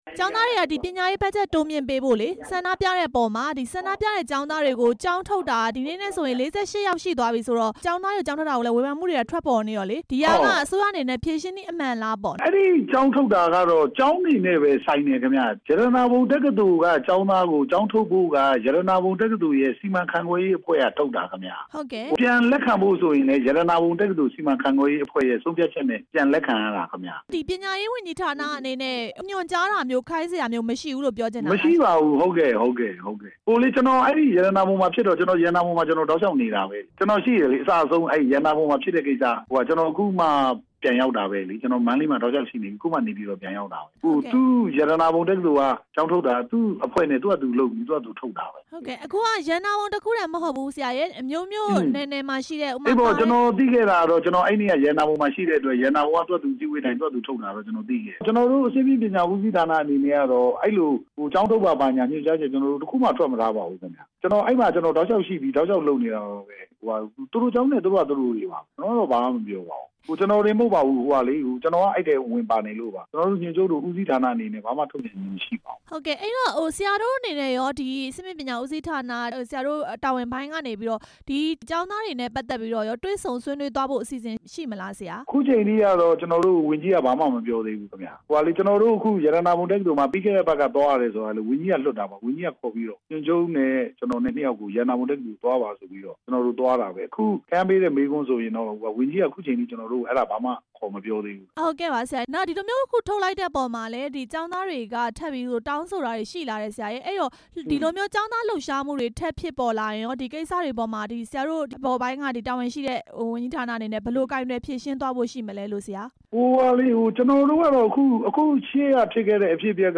ဆန္ဒပြကျောင်းသားတချို့ ကျောင်းထုတ်ခံရတဲ့အကြောင်း မေးမြန်းချက်